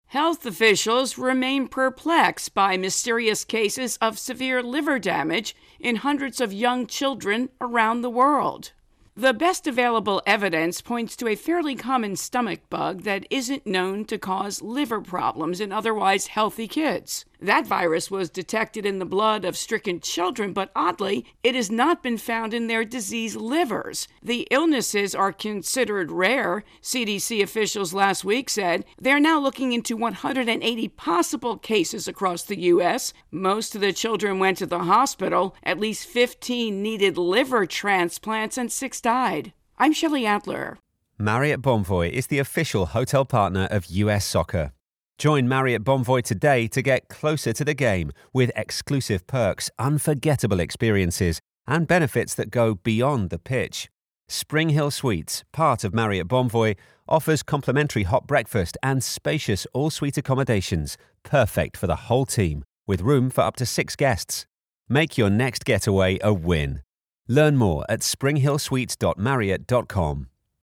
Liver intro and voicer